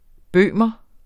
Udtale [ ˈbøˀmʌ ]